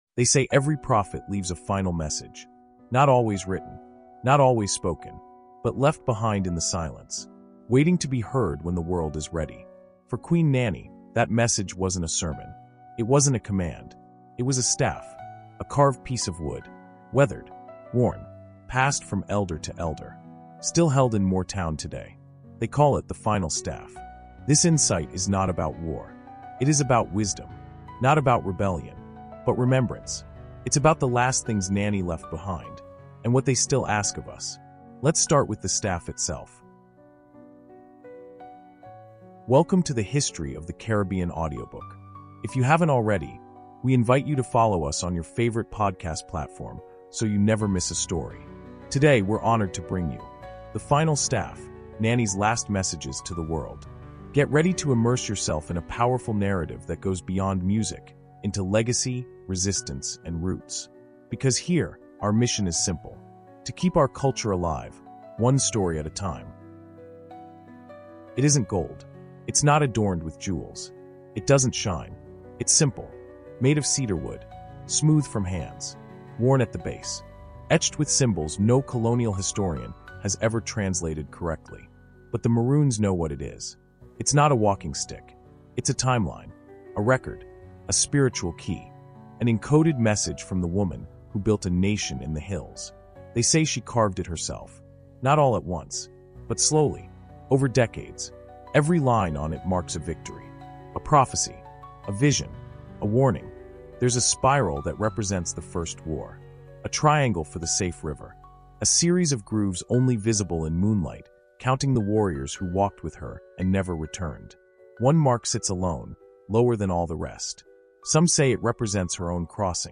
In this soul-stirring 20-minute audio insight, we uncover the deeper meaning behind the legendary “Final Staff” Nanny left behind.